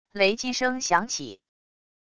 雷击声响起wav音频